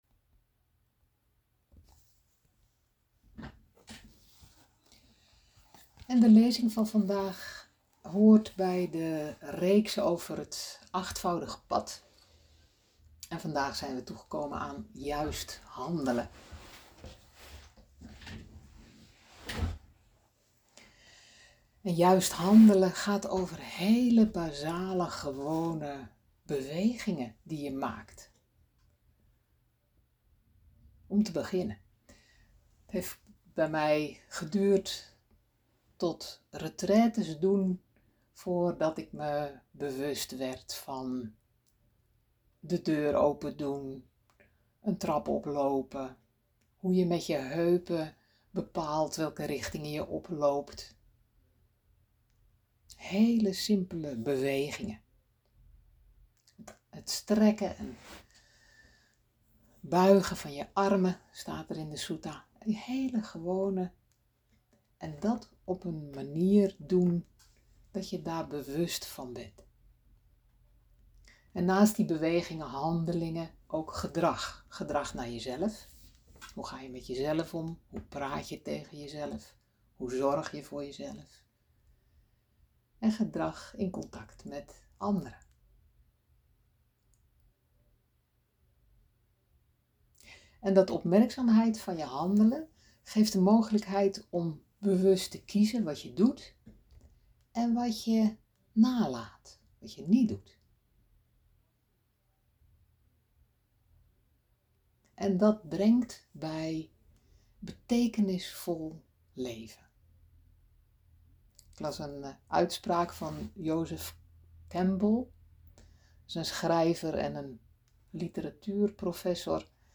Lezing-juist-handelen-drijfveren-voor-gedrag-4-feb-26.mp3